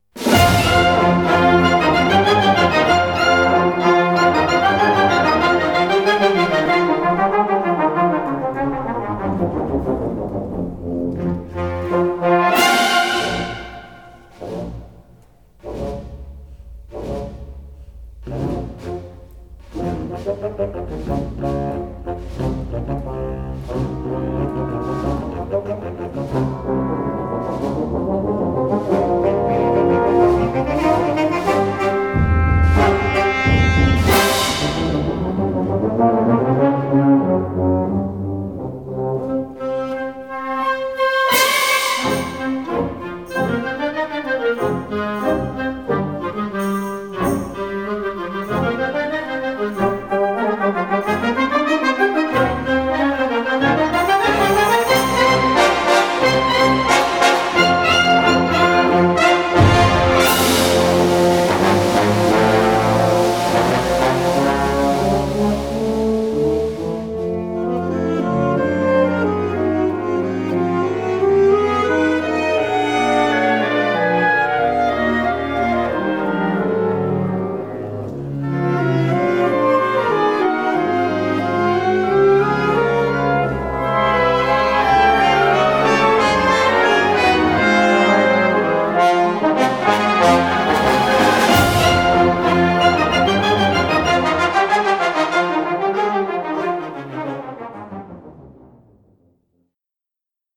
Kategorie Blasorchester/HaFaBra
Unterkategorie Symphonische Bearbeitungen
Besetzung Ha (Blasorchester)